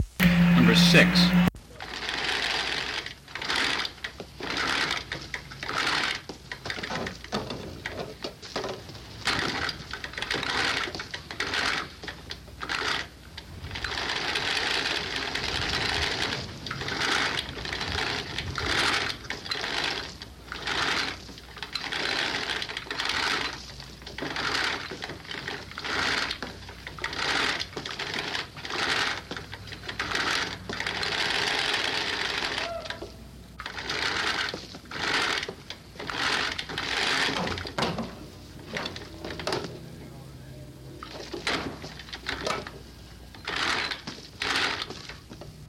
古老的吱吱声 " G2703金属曲柄
描述：缺口金属曲柄相互对立。像链轮一样。一些滑动金属噪音。
我已将它们数字化以便保存，但它们尚未恢复并且有一些噪音。